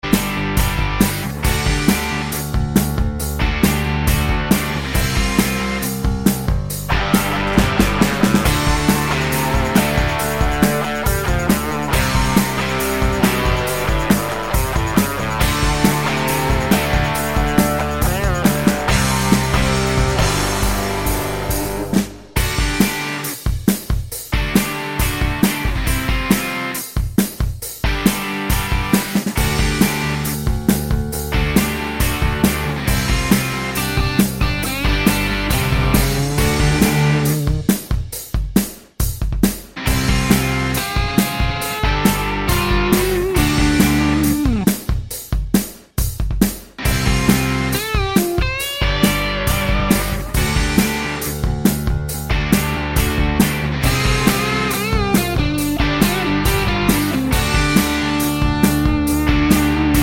no Backing Vocals Indie / Alternative 4:17 Buy £1.50